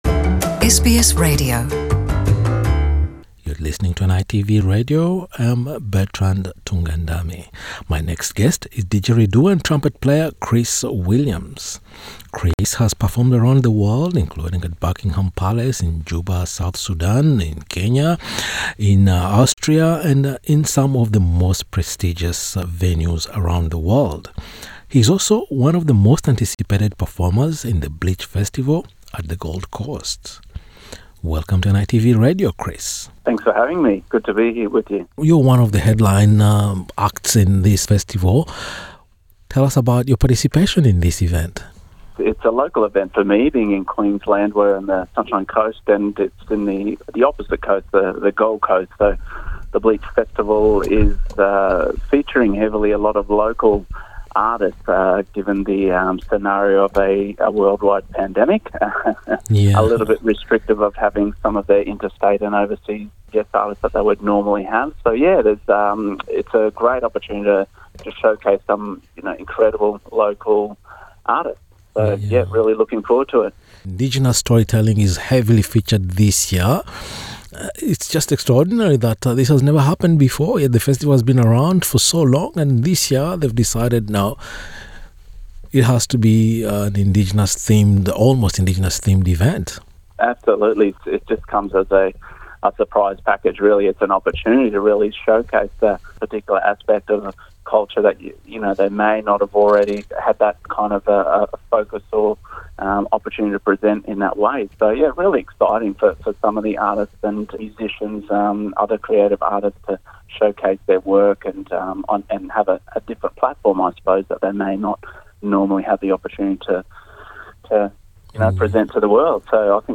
In a conversation with NITV Radio,